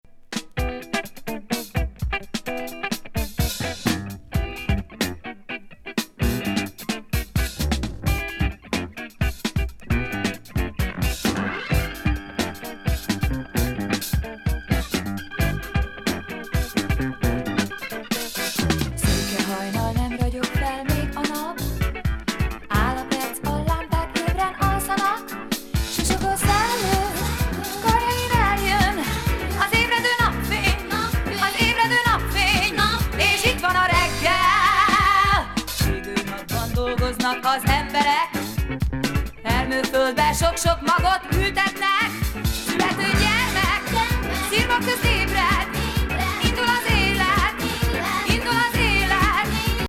のグルーヴィー・ボーカル作。ファンキー・ディスコ
メロウ・ソウル